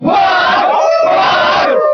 Category:Crowd cheers (SSBB) You cannot overwrite this file.
Wolf_Cheer_French_SSBB.ogg.mp3